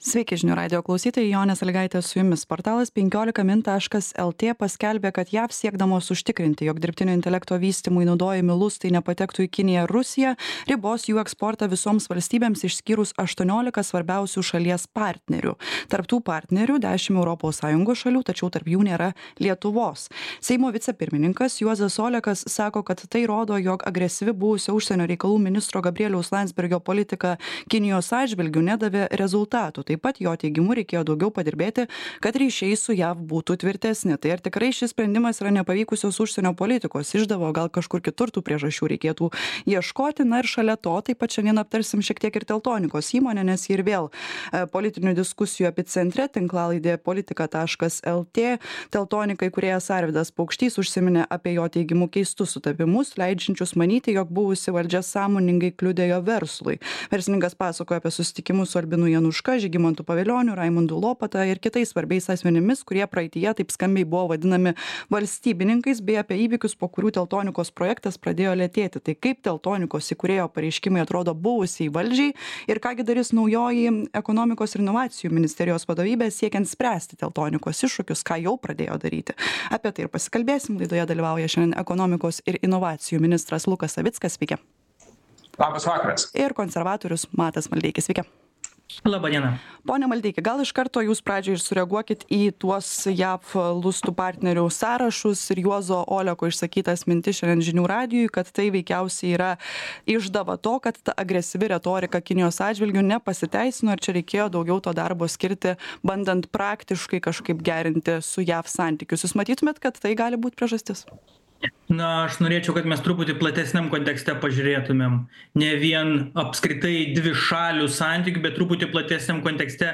Laidoje dalyvauja ekonomikos ir inovacijų ministras Lukas Savickas ir konservatorius Matas Maldeikis.